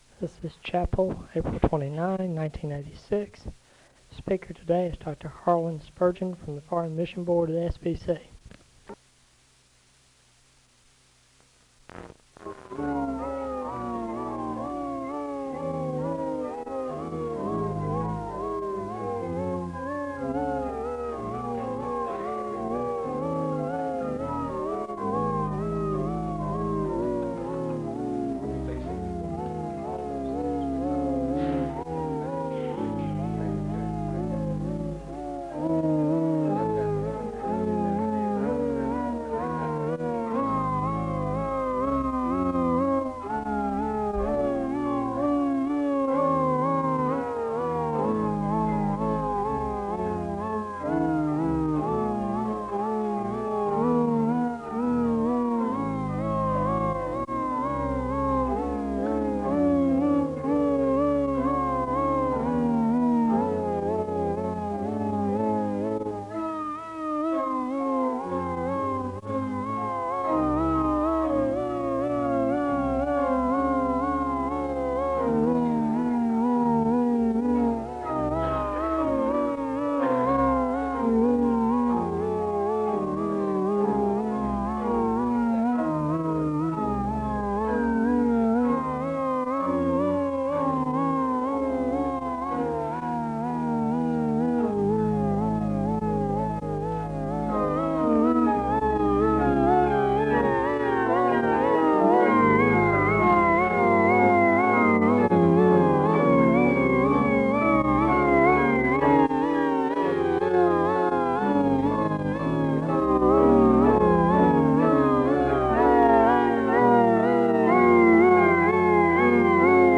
The service begins with organ music (0:00-3:46). An announcement is made for a speaker the next day (3:47-4:06). Prayer concerns are shared with the congregation and there is a moment of prayer (4:07-7:01).
The choir sings an anthem (8:21-12:09).
Location Wake Forest (N.C.)